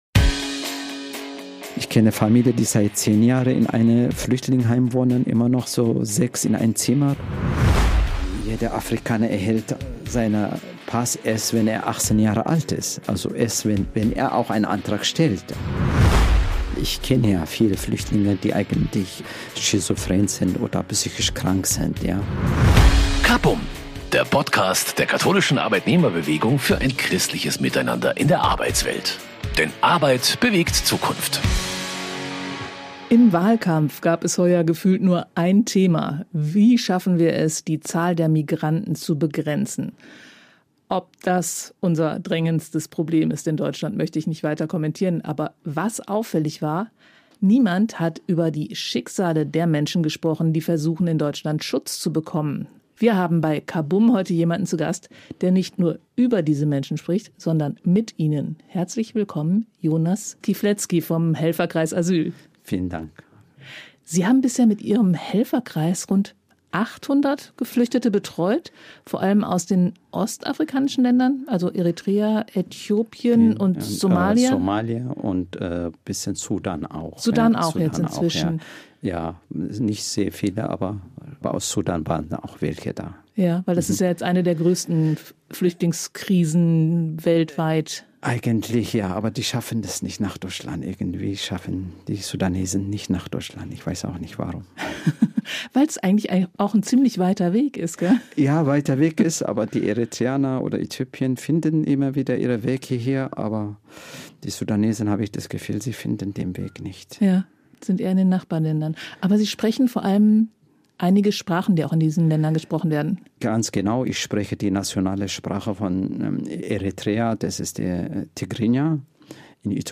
In dieser Folge hören Sie außerdem, warum es für viele Menschen aus Afrika so schwer ist, ihre Identität nachzuweisen – und wie sie und ihre Kinder darunter leiden. Dass viele Flüchtlinge psychisch krank sind, bestätig unser Studiogast. Er spricht über die Ursachen und beschreibt Hürden, die verhindern, dass diesen Menschen wirksam geholfen wird.